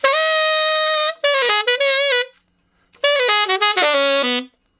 068 Ten Sax Straight (Ab) 27.wav